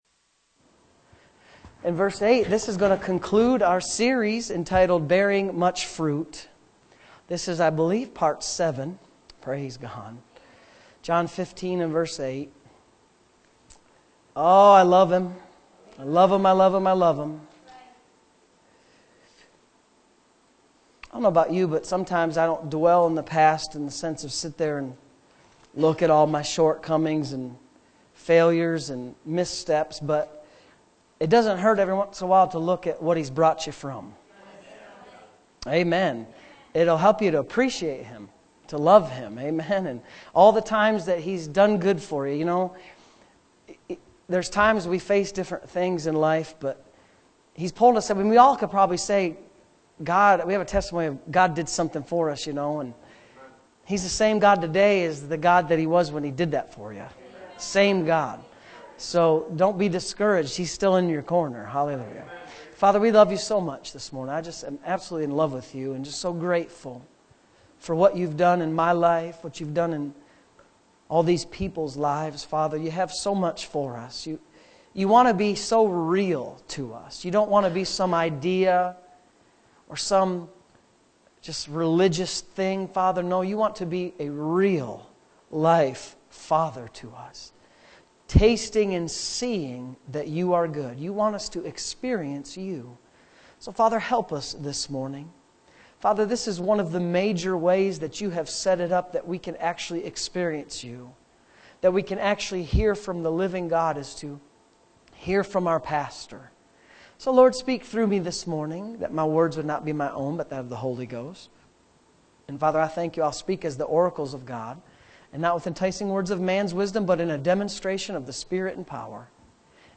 Bearing Much Fruit Tagged with Sunday Morning Services